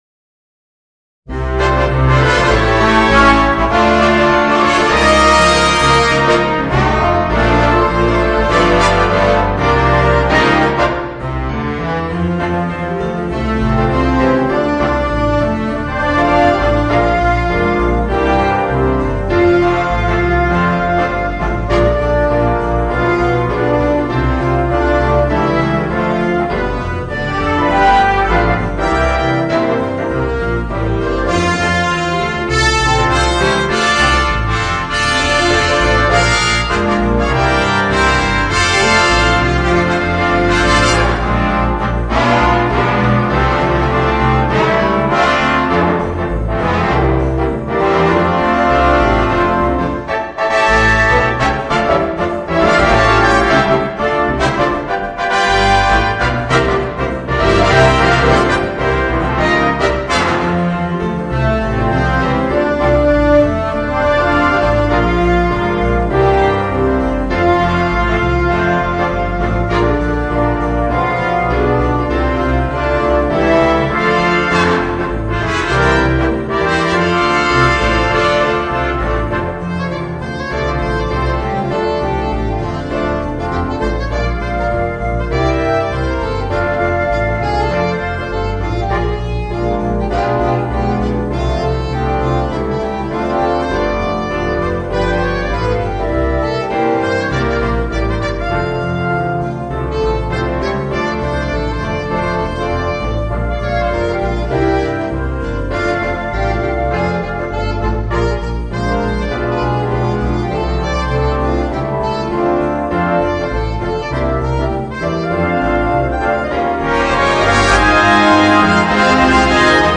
Orchestre à Vent